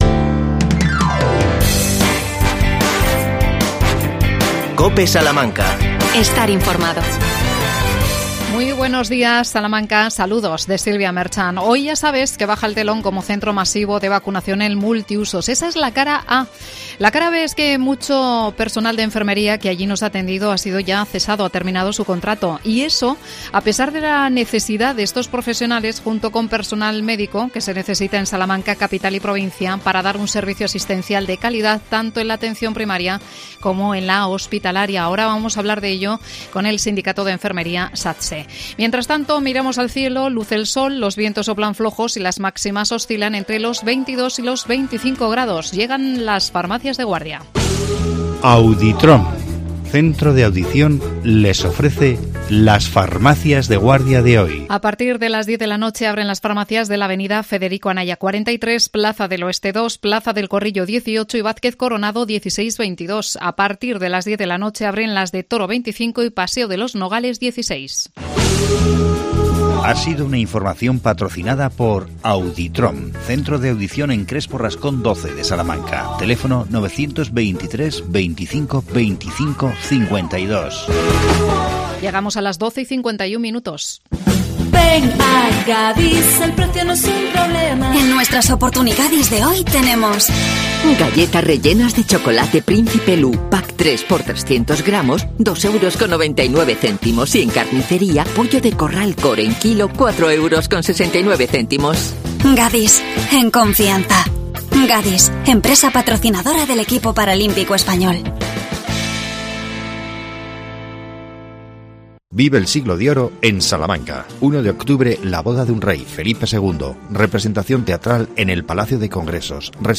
AUDIO: Satse Salamanca denuncia carencias para los profesionales en el nuevo hospital. Entrevistamos